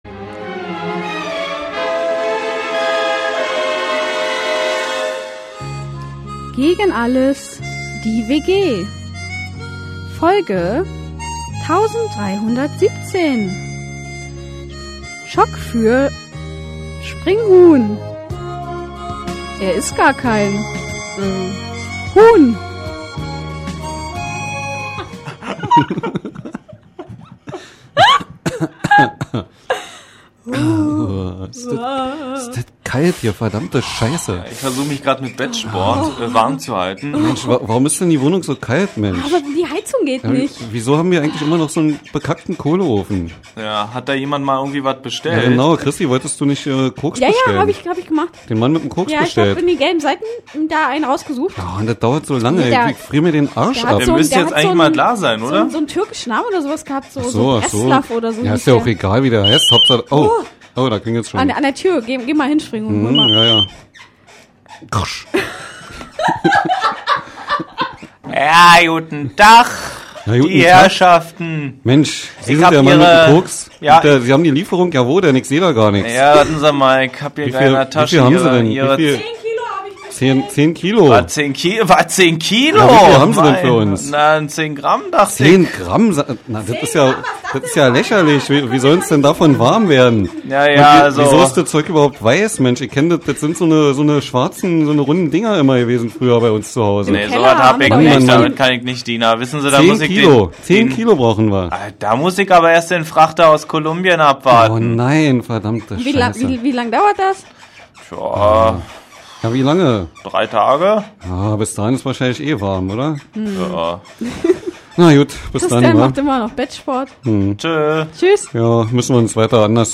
Live-Hörspiel